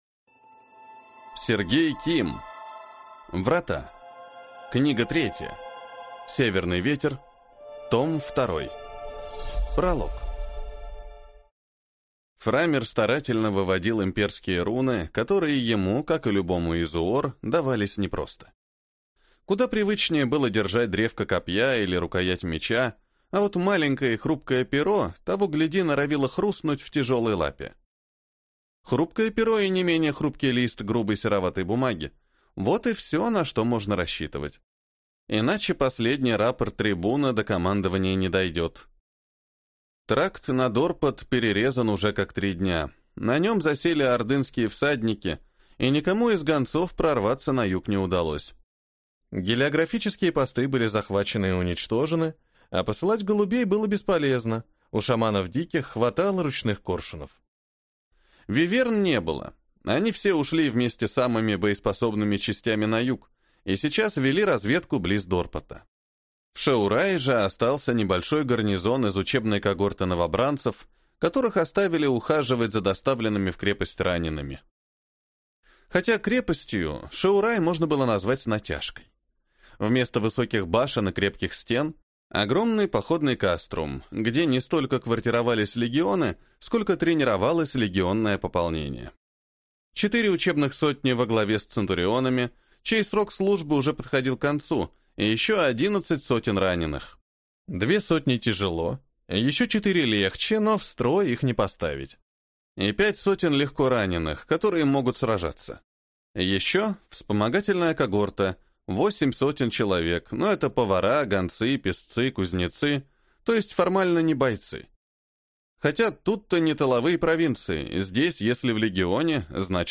Аудиокнига Врата. Книга 3. Северный ветер. Том 2 | Библиотека аудиокниг